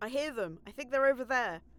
Voice Lines / Combat Dialogue
becca I hear them theyre there.wav